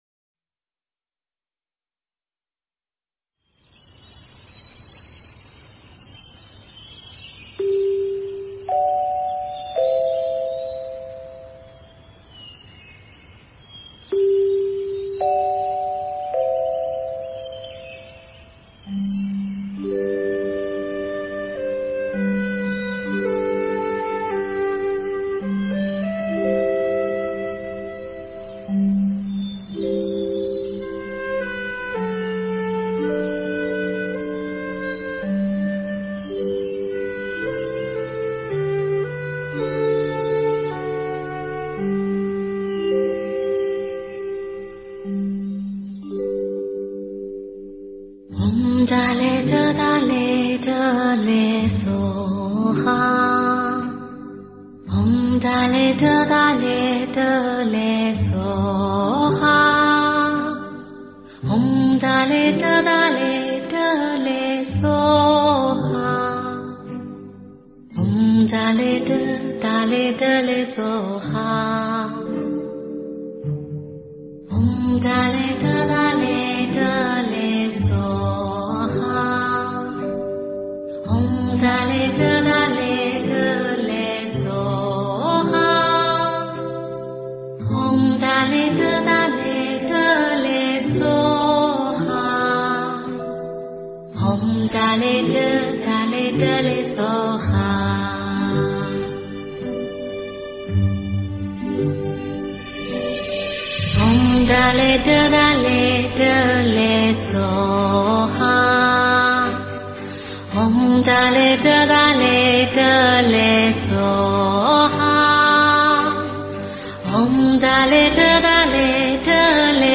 绿度母心咒--推荐 真言 绿度母心咒--推荐 点我： 标签: 佛音 真言 佛教音乐 返回列表 上一篇： 吉祥天母心咒--仁波切 下一篇： 观世音菩萨圣号--本善本幻 相关文章 佛教遗经（念诵） 佛教遗经（念诵）--海涛法师...